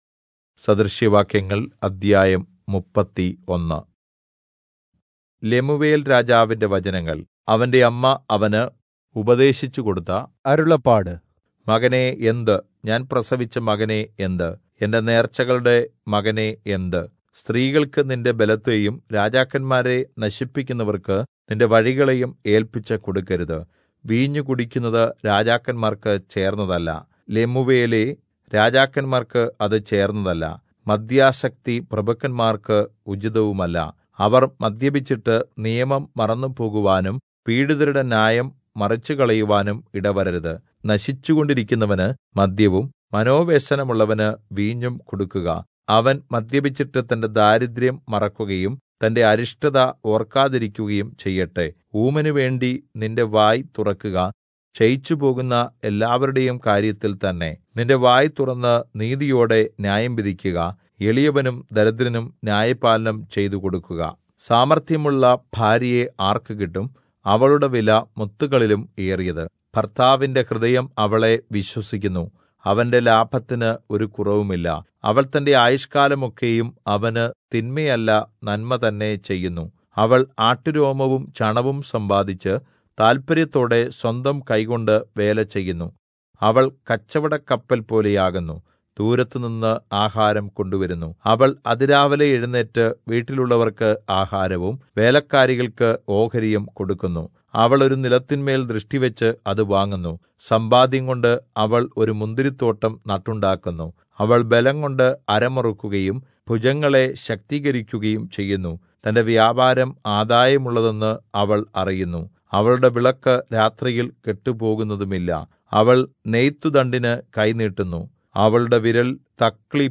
Malayalam Audio Bible - Proverbs 17 in Irvml bible version